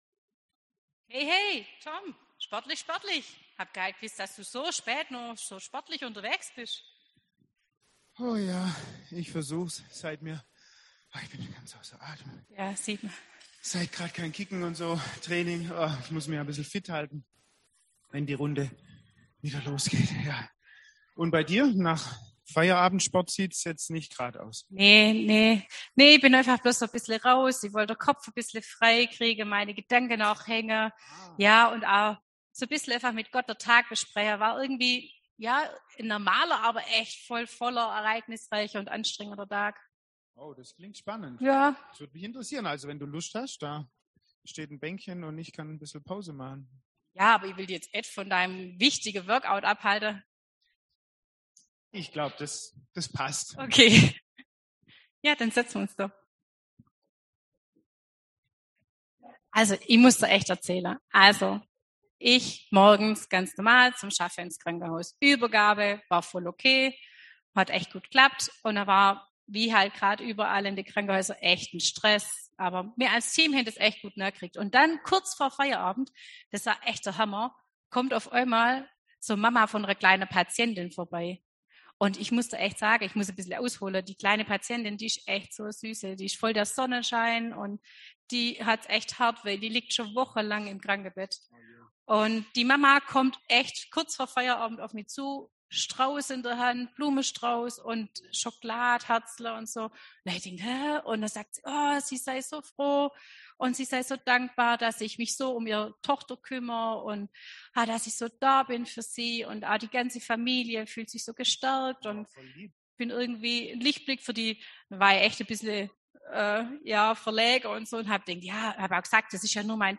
Dialog und Predigt im Gottesdienst mit Familien zu Lukas 10,25-37.